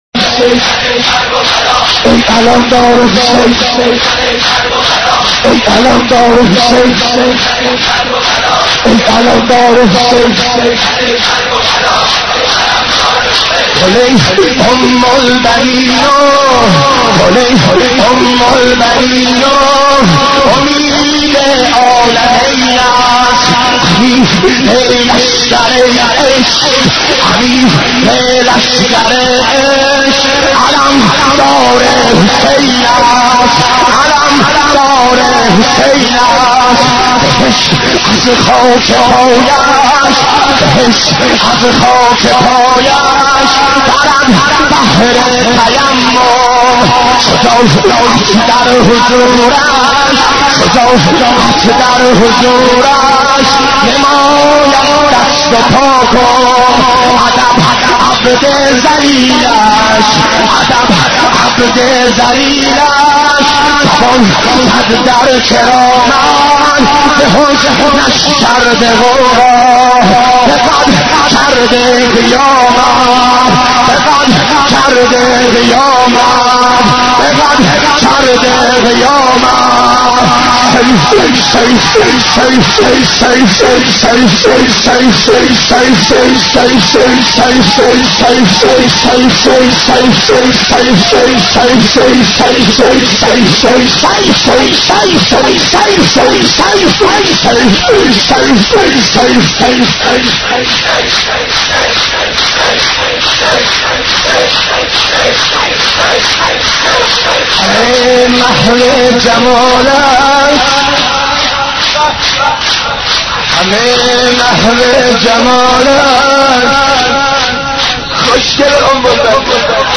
حضرت عباس ع ـ شور 33